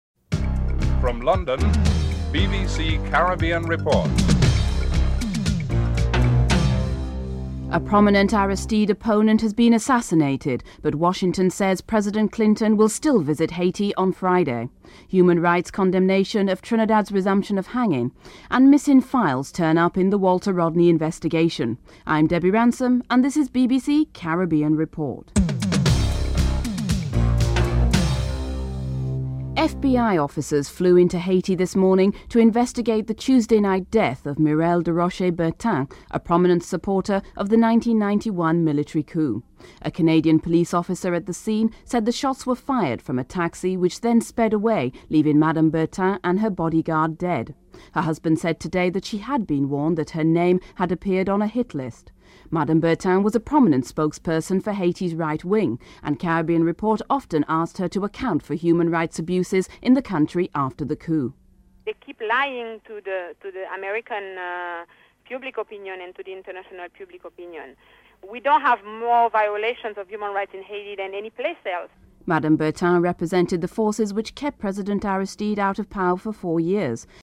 9. Recap of top stories (14:12-14:55)